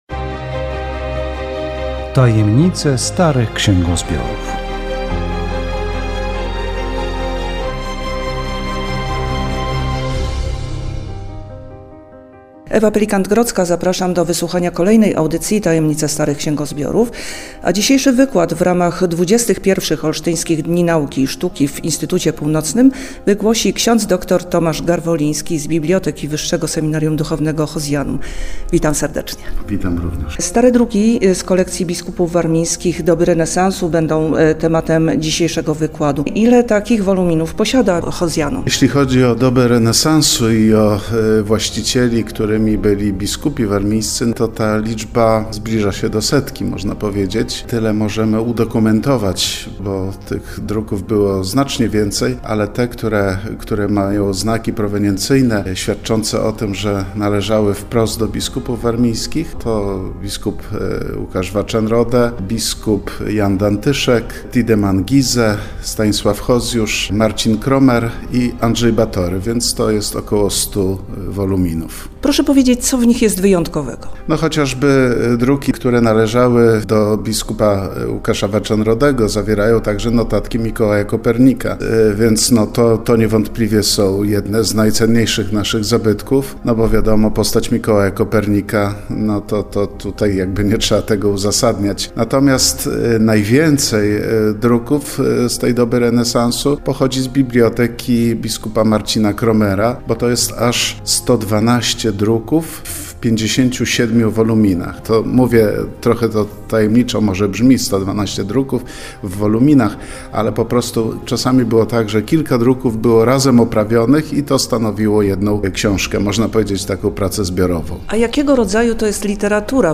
Audycja radiowa "Tajemnice starych księgozbiorów